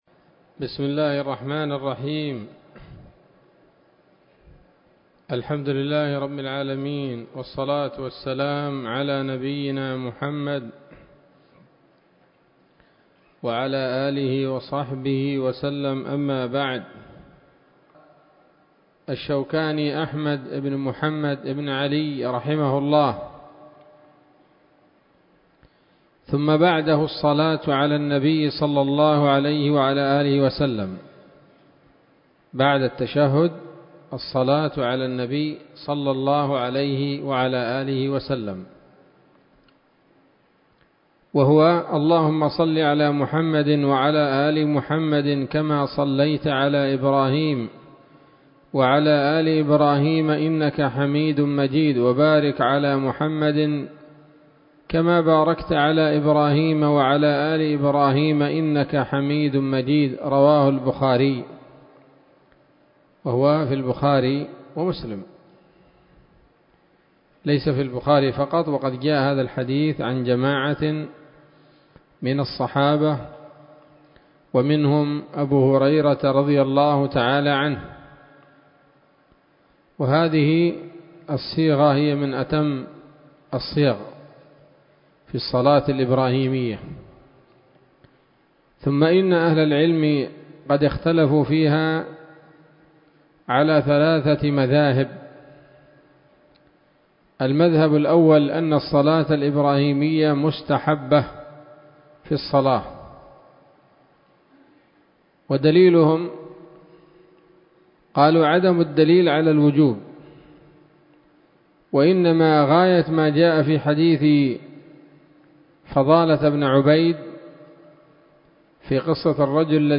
الدرس الرابع عشر من كتاب الصلاة من السموط الذهبية الحاوية للدرر البهية